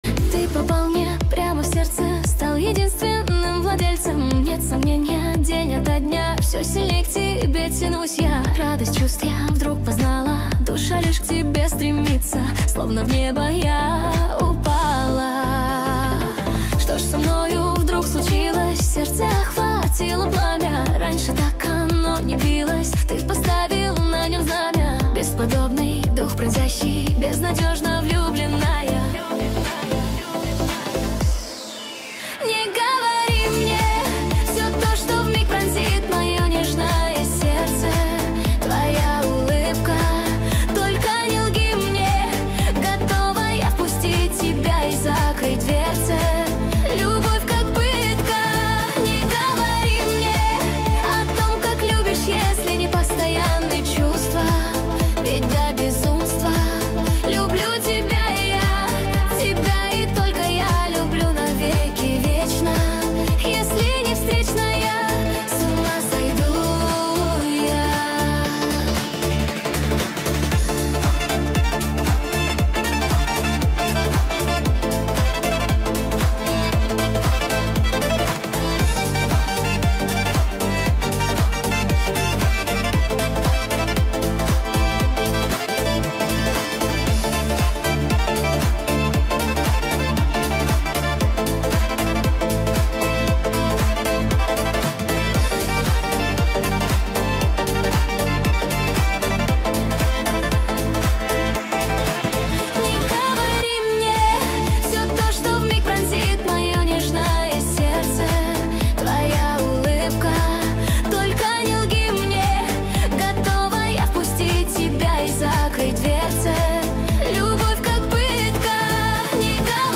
Lezginka remix